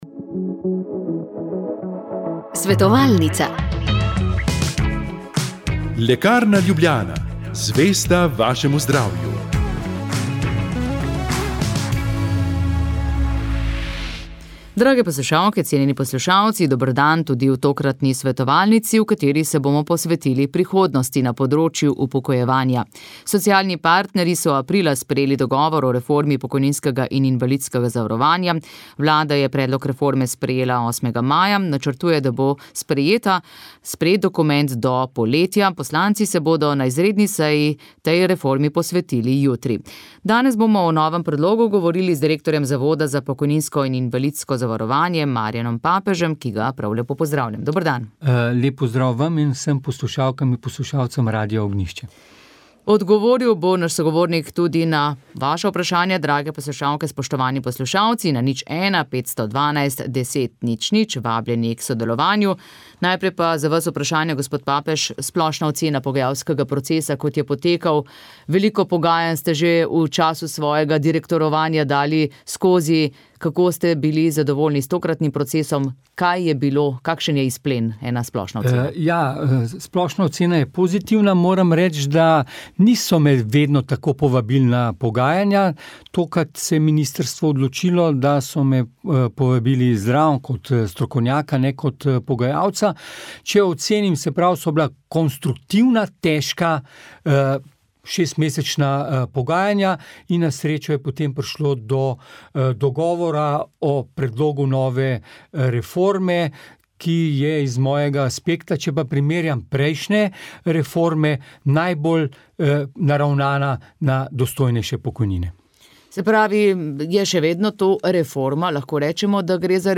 Ob klicih poslušalcev